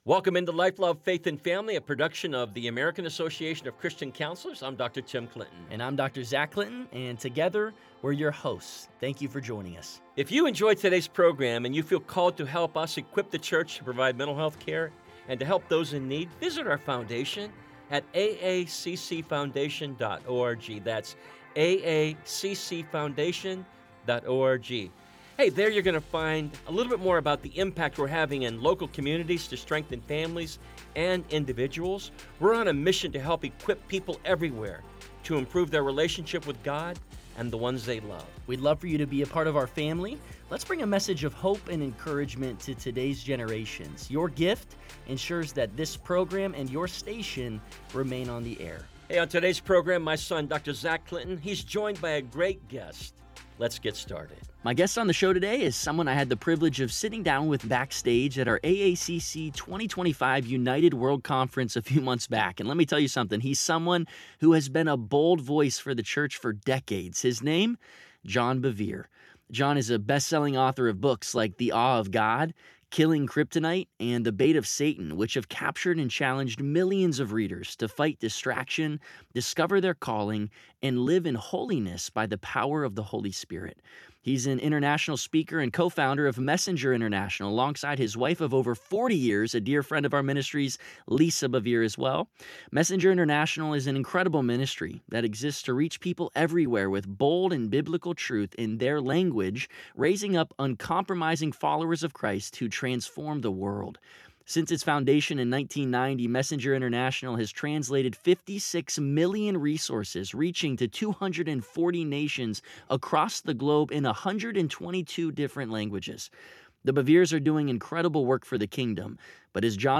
This conversation offers comfort and hope to those nearing life’s end or walking beside a loved